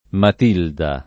Matilde [mat&lde] pers. f. — come nome della «gran contessa» (M. di Canossa, 1046-1115), anche Matilda [